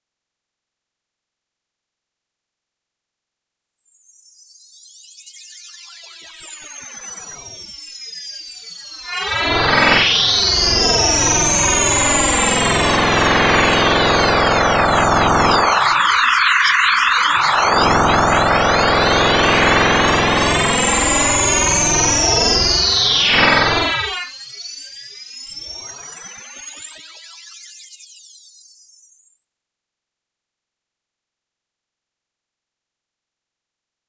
Sonification of Mask Study